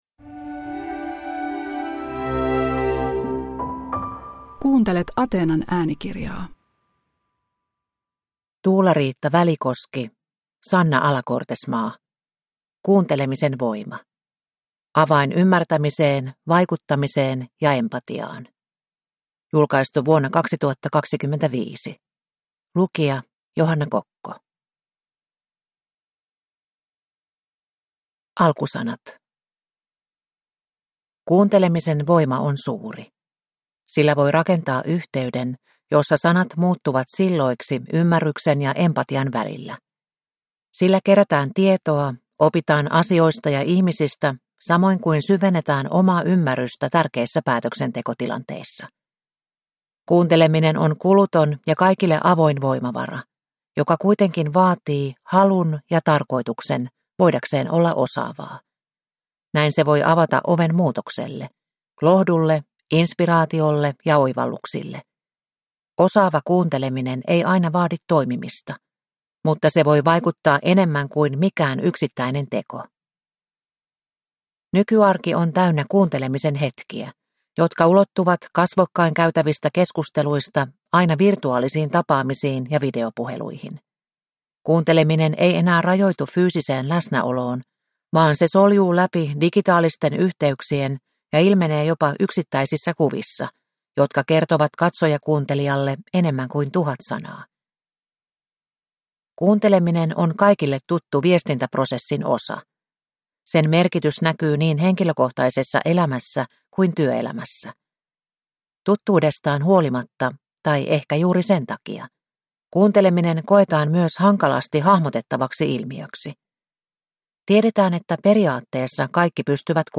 Kuuntelemisen voima – Ljudbok